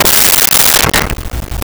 Glass Bottle Break 04
Glass Bottle Break 04.wav